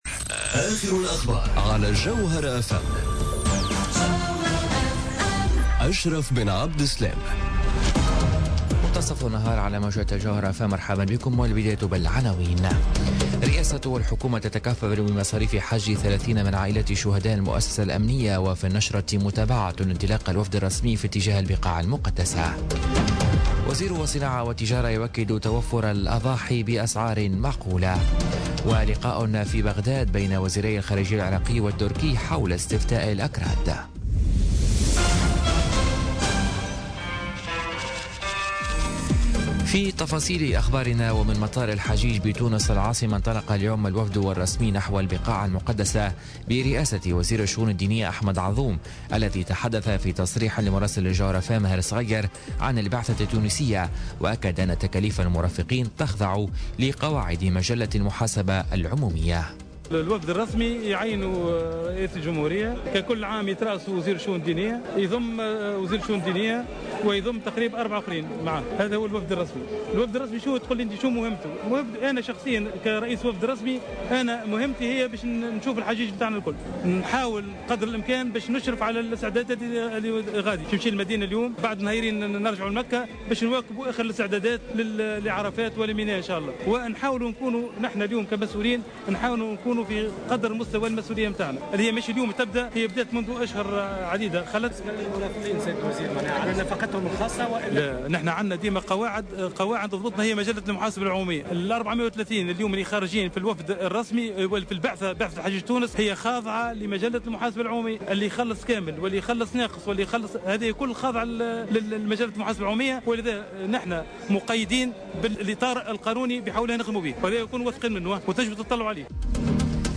نشرة أخبار منتصف النهار ليوم الإربعاء 23 أوت 2017